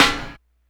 snare04.wav